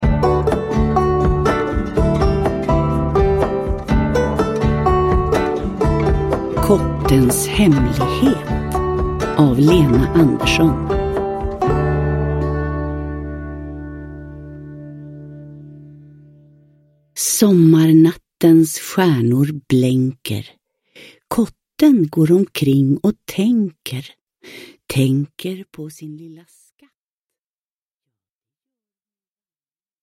Kottens hemlighet – Ljudbok – Laddas ner